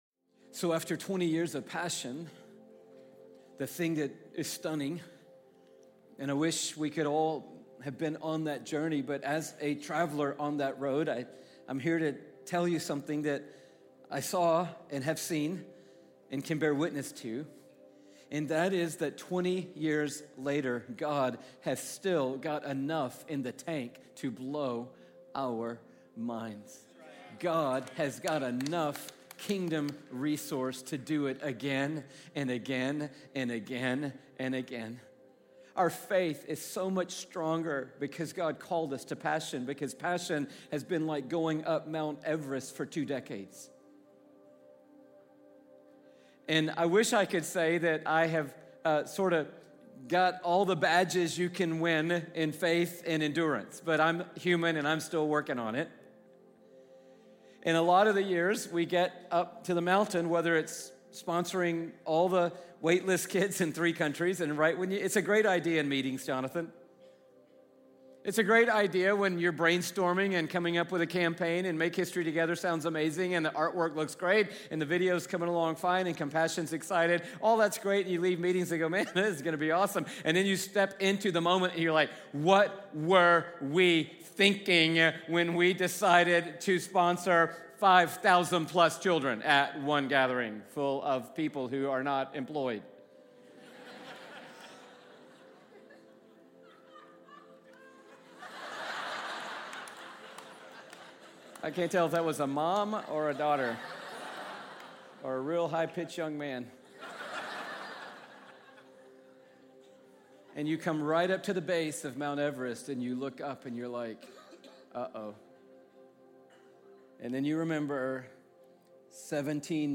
Christianity, Passion, Religion & Spirituality, Passionconferences, Messages, Louiegiglio, Sermons, Passioncitychurch, Church